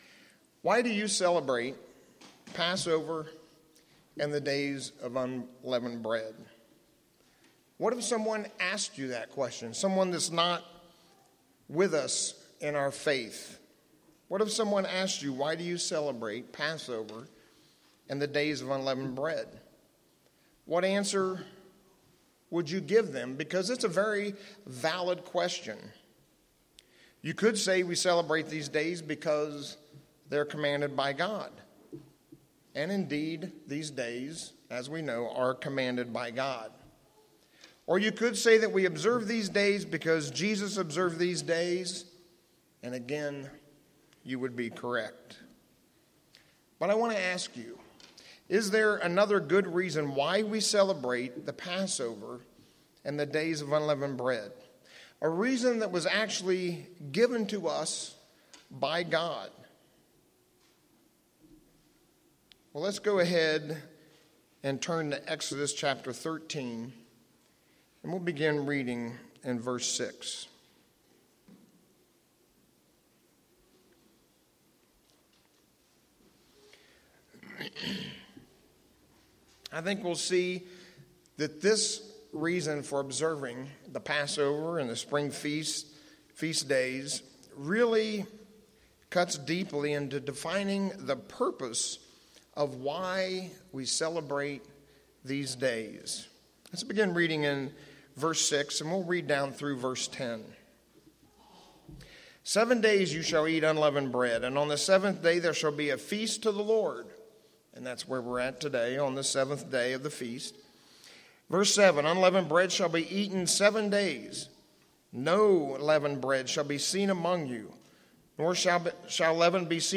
As we go through the sermon today we'll see another reason why we observe these days.